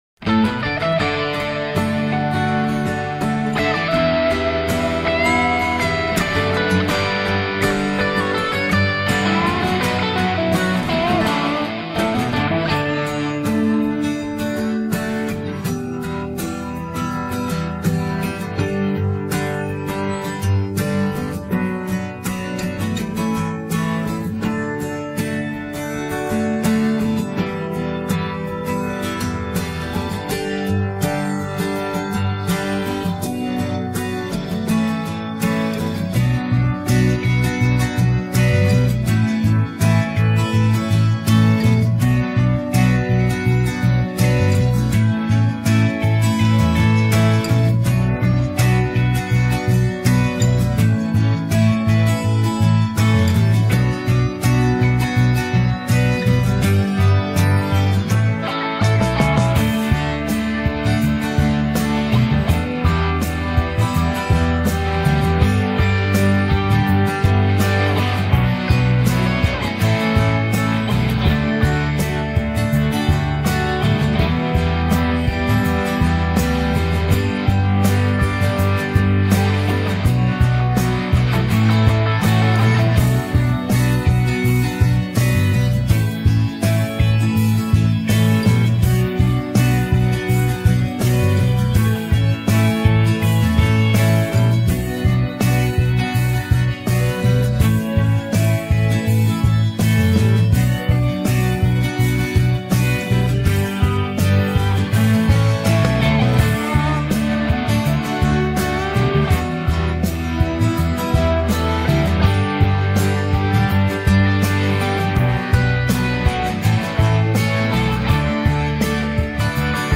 Quatre-elements-accompagnement-instrumental.mp3